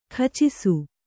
♪ khacisu